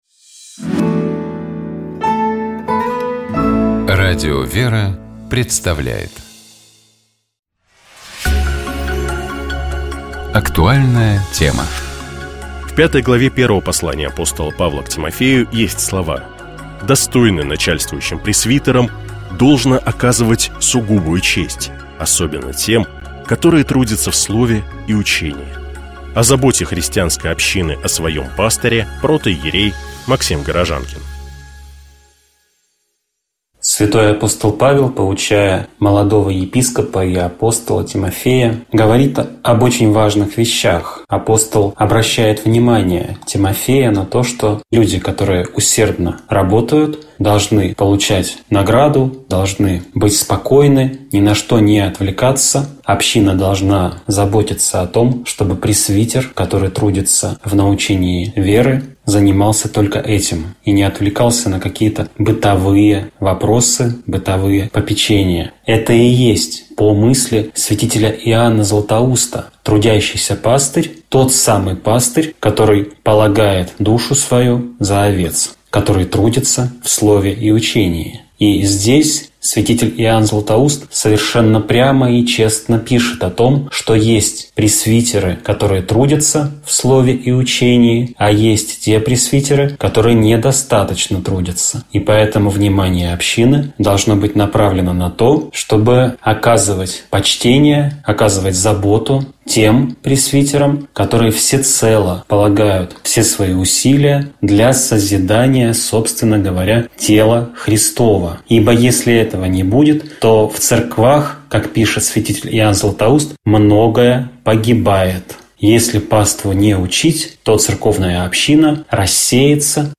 протоиерей